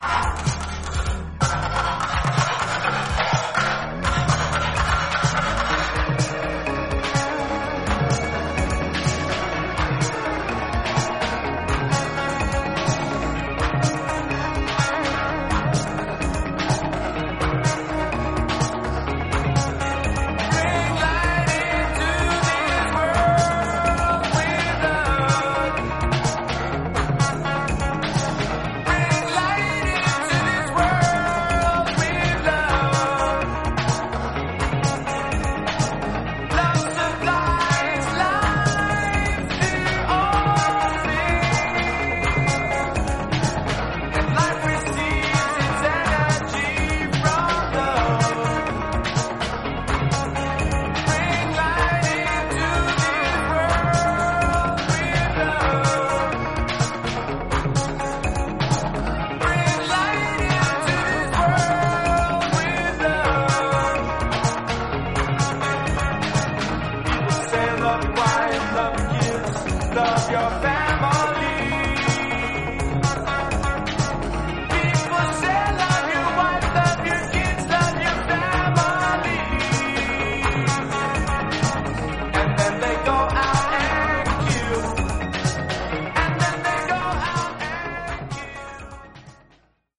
80年代らしいバレアリック感のある、ニューウェーヴ/シンセ・ポップ/フュージョン等を展開します。